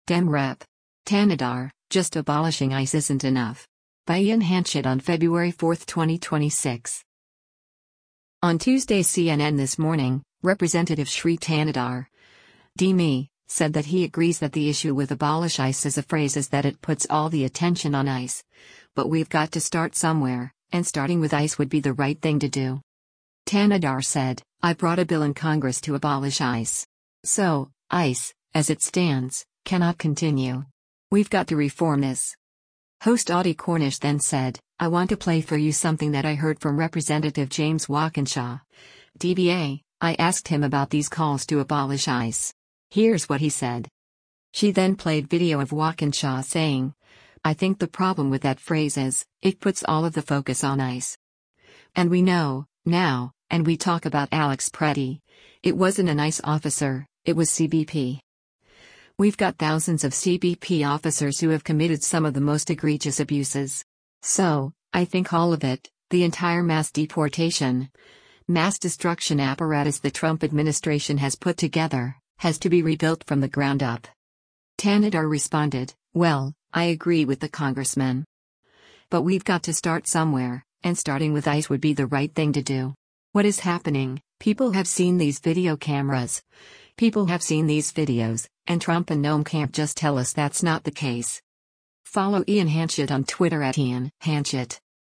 Host Audie Cornish then said, “I want to play for you something that I heard from Rep. James Walkinshaw (D-VA), I asked him about these calls to abolish ICE. Here’s what he said:”